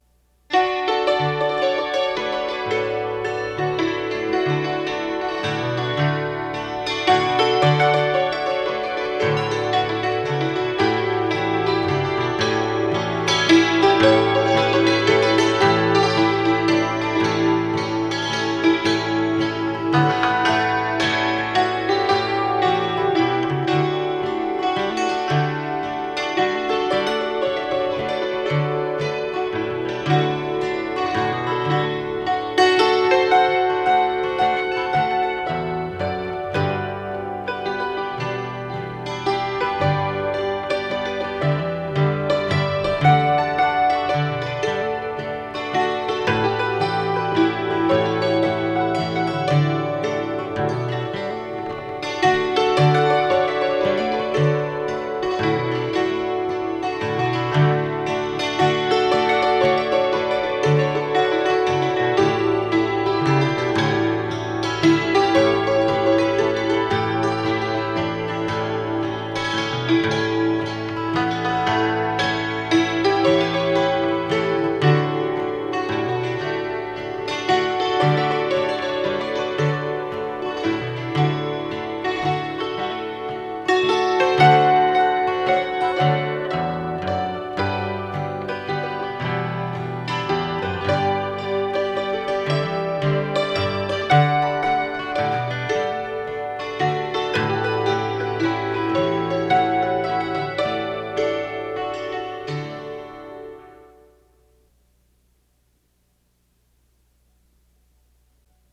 It was recorded in what was for many years the fair office.